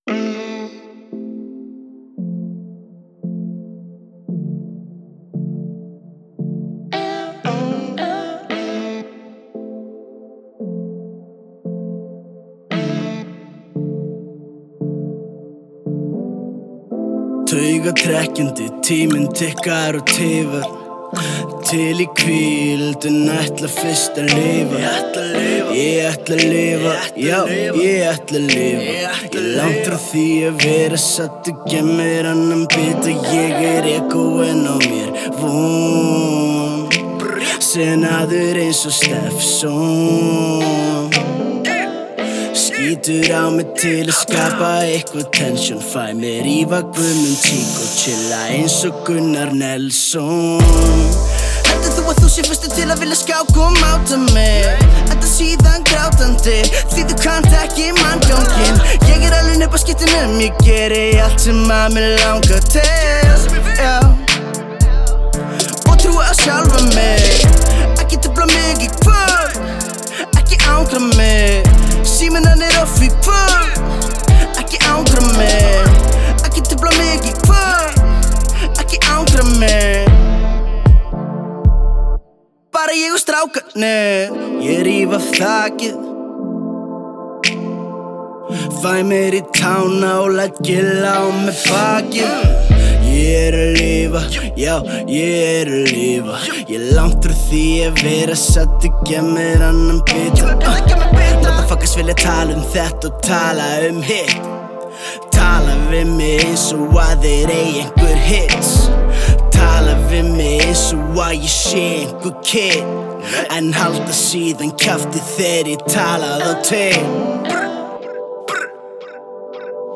a 2015 self-released single.